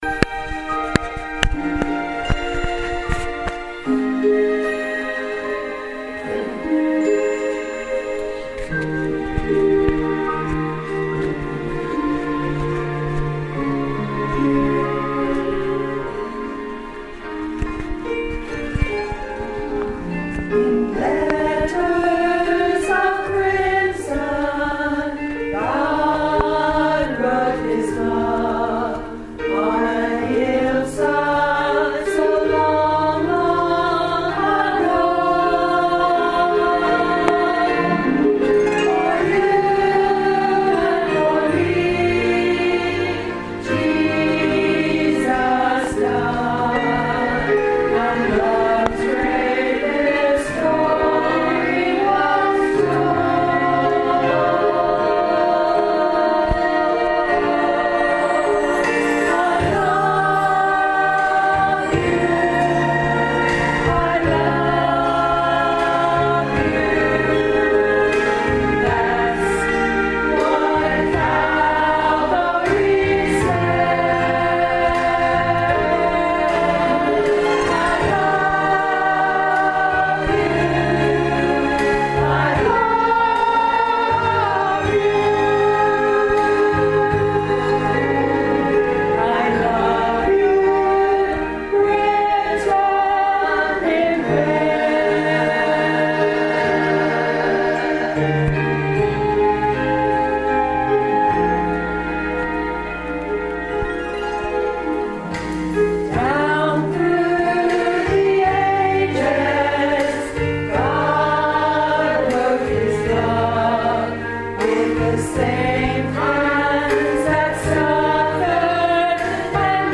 Second sermon in a Series on Covenant